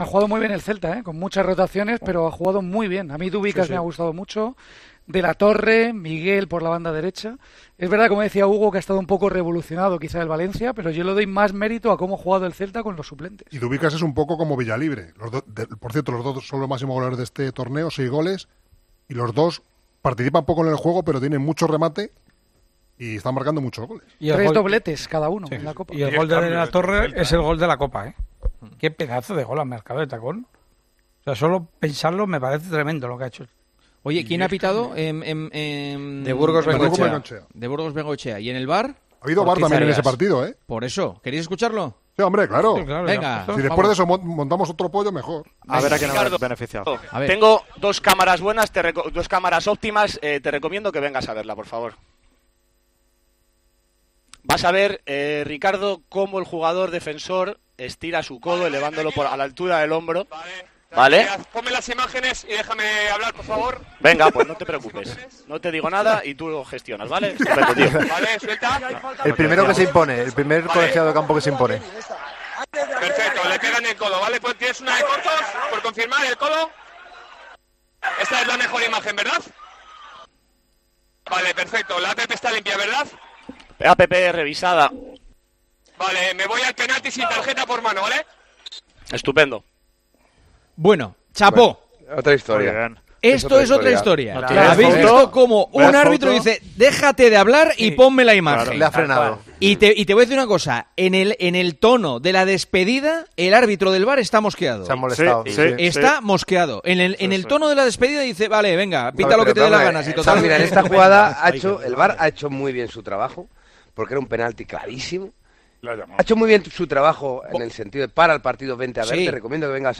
Los tertulianos de El Partidazo de COPE analizan el audio del VAR del Valencia-Celta